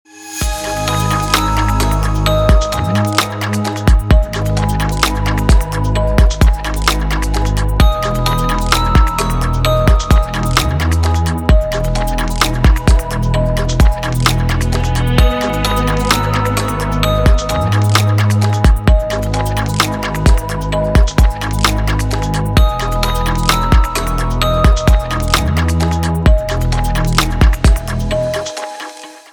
Будильник на телефон, рингтоны и мелодии для будильника